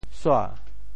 潮州府城POJ suá 国际音标 [sua]
sua2.mp3